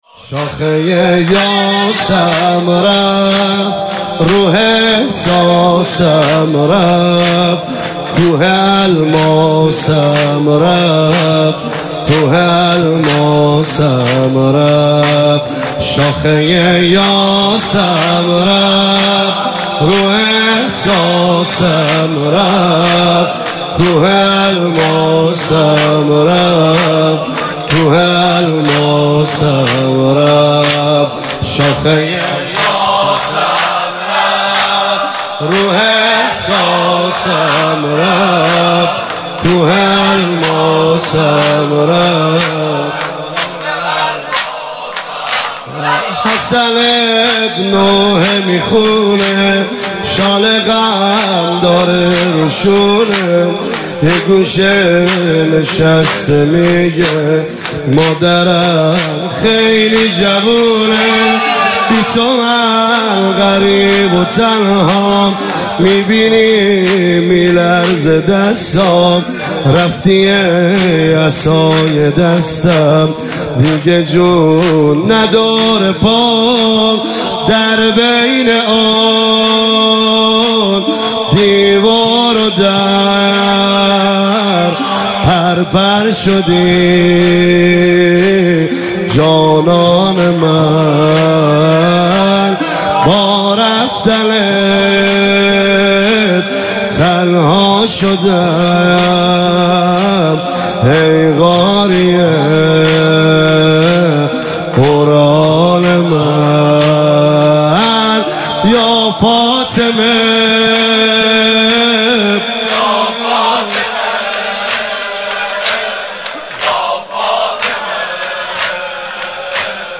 مراسم ایام فاطمیه دوم